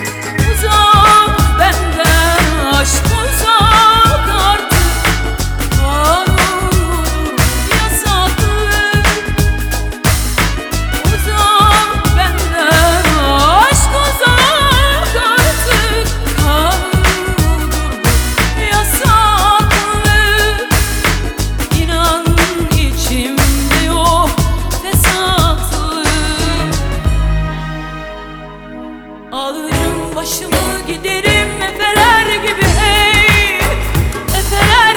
Жанр: Турецкая поп-музыка / Поп